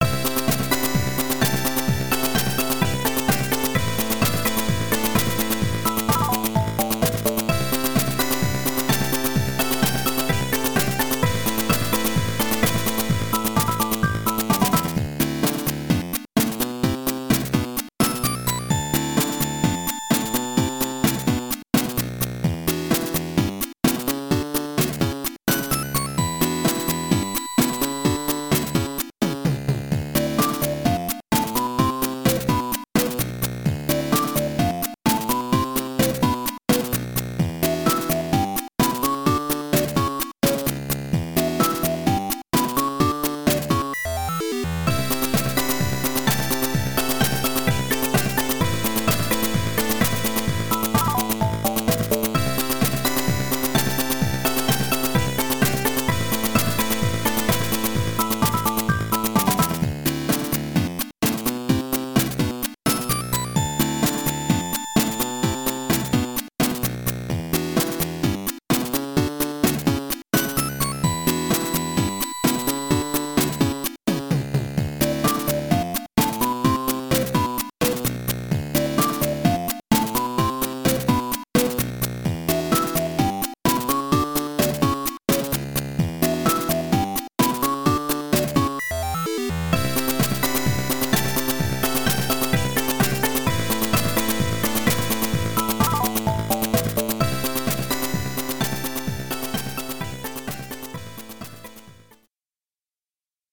This music was recorded using the game's sound test.